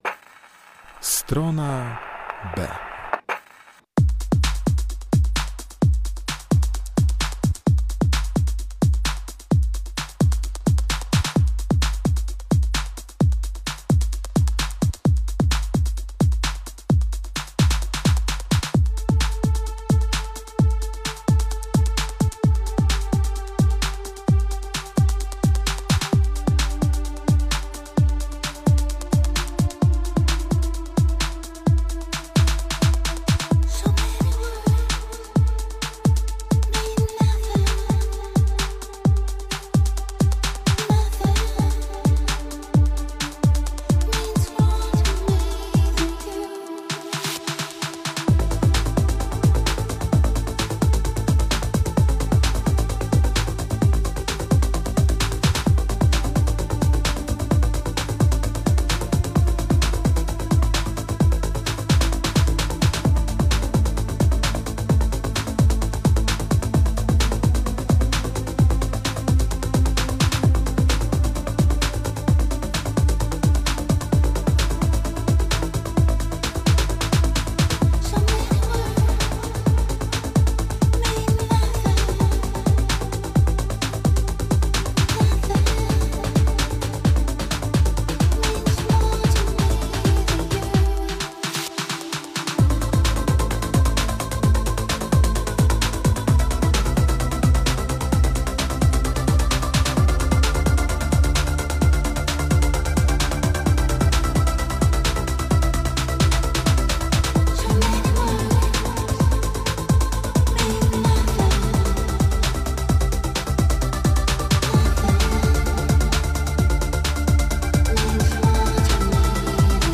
Muzyka elektroniczna